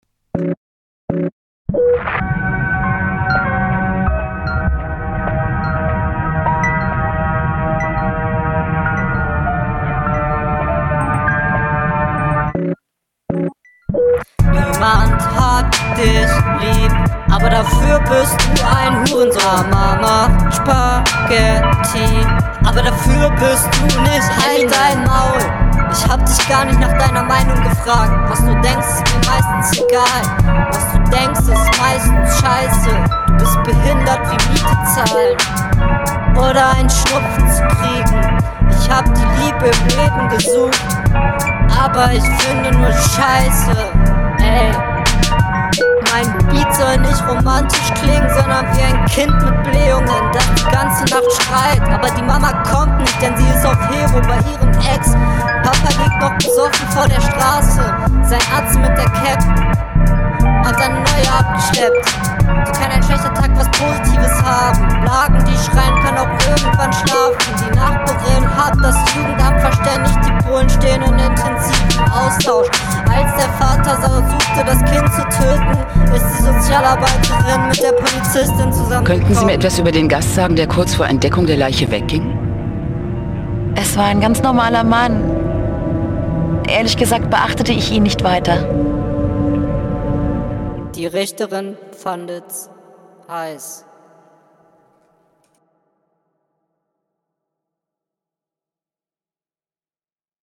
Warum ist das links so viel lauter als rechts? Der Beat ist cool.